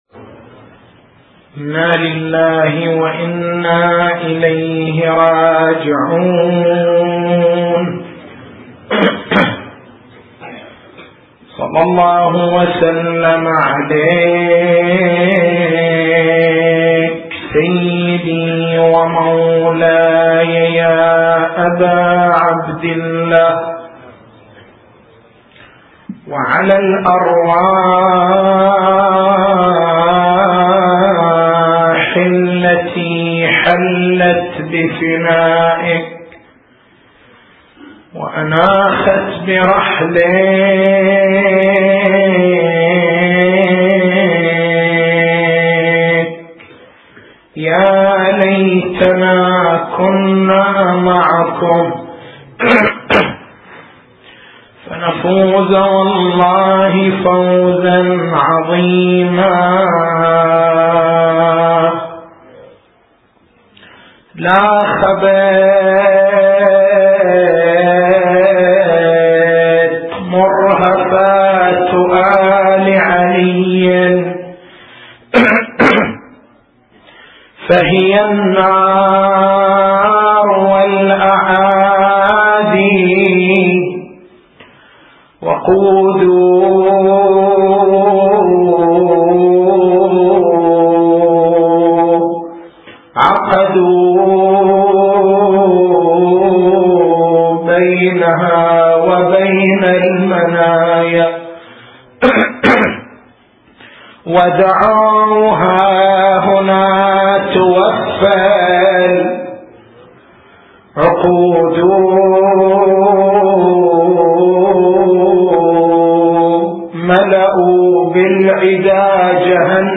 تاريخ المحاضرة: 13/01/1429 نقاط البحث: معنى الغيب عناصر الانفتاح على الغيب العنصر الروحي العنصر الفكري نوافذ الانفتاح على الغيب وقفة مع الاستخارة سلبيات التعاطي الغيبي التعامل مع الطرق غير المشروعة الإفراط في التعاطي وتعطيل العقل التسجيل الصوتي: تحميل التسجيل الصوتي: شبكة الضياء > مكتبة المحاضرات > محرم الحرام > محرم الحرام 1429